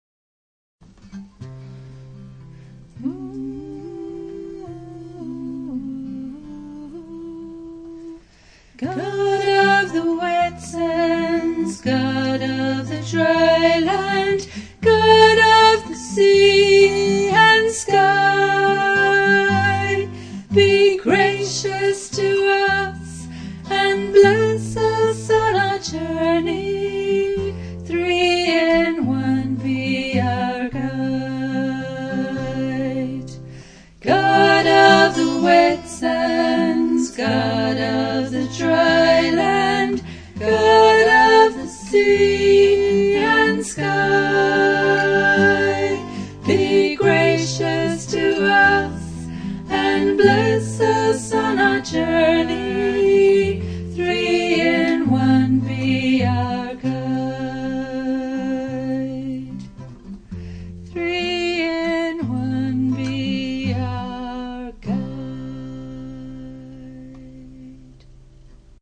Shorter, more meditative, songs